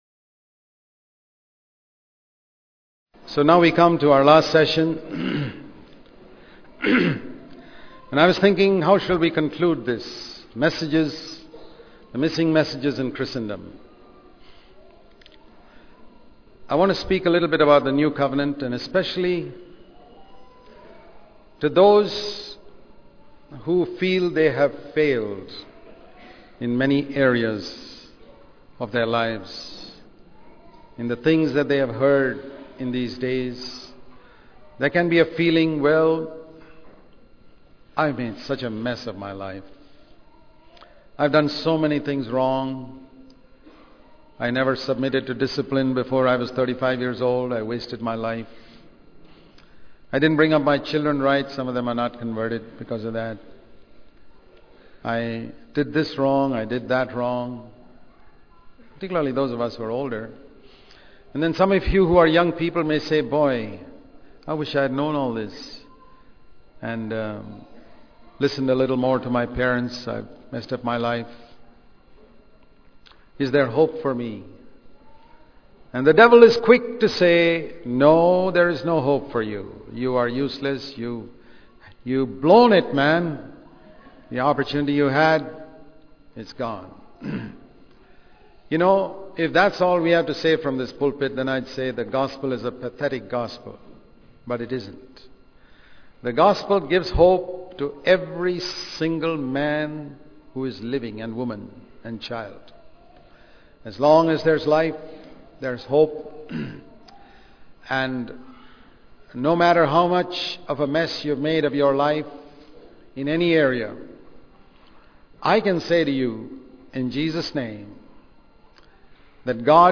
10.You Who Have Failed Will Be A Blessing The Missing Messages In Today's Christianity Bangalore Conference 2006 sermons.